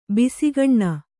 ♪ bisigaṇṇa